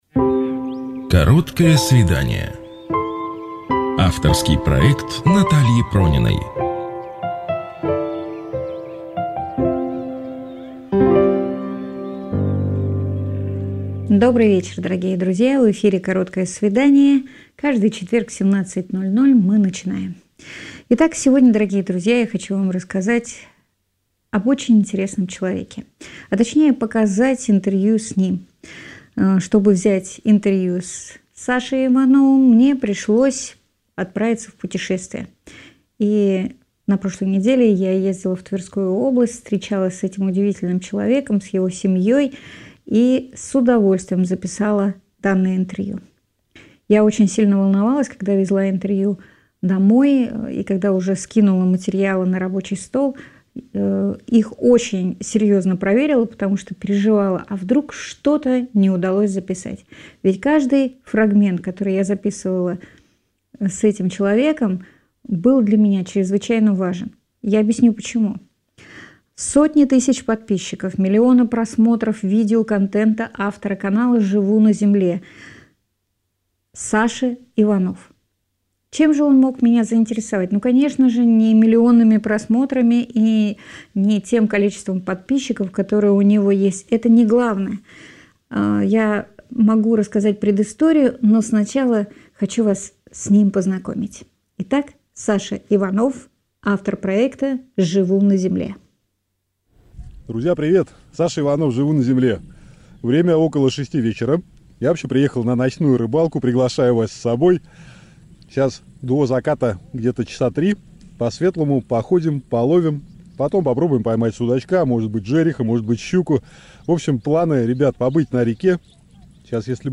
Гости студии: священники, артисты, бизнесмены, люди самых разных